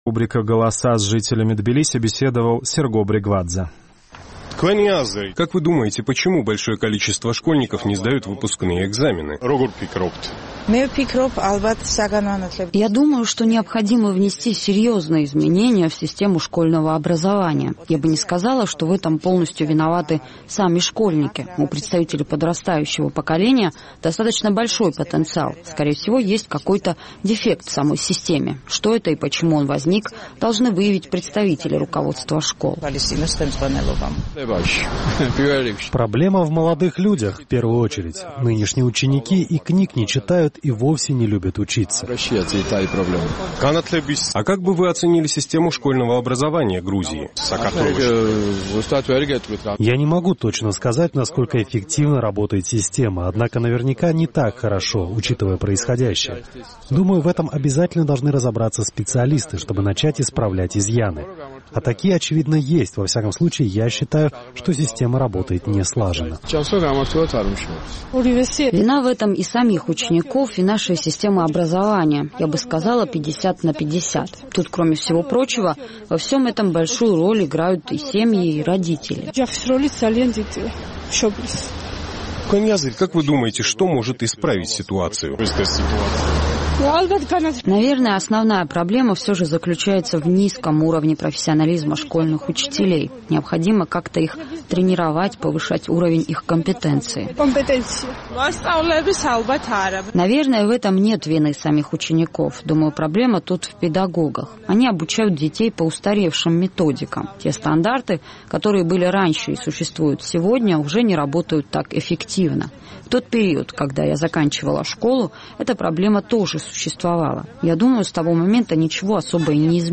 По предварительным данным Национального центра экзаменов и оценок Грузии, 11000 из 48000 учеников школ не сдали выпускные экзамены. Мнением рядовых граждан о том, почему сложилась такая ситуация, поинтересовался наш тбилисский корреспондент.